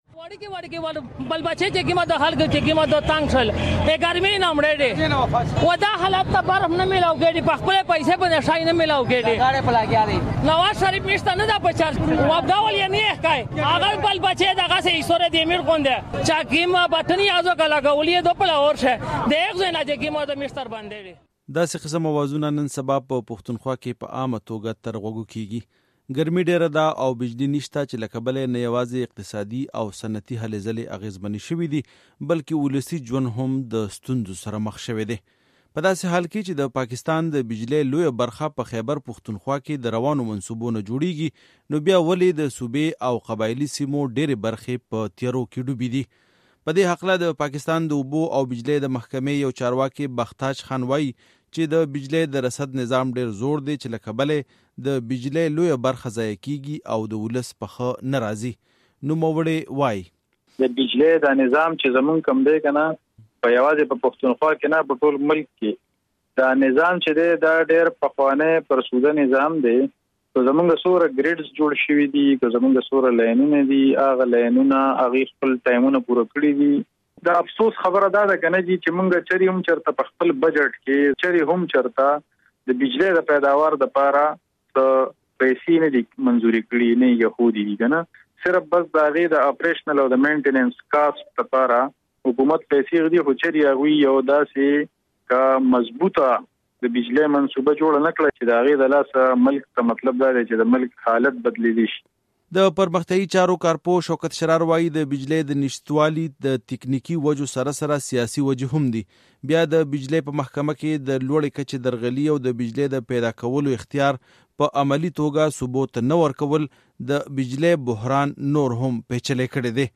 رپوټ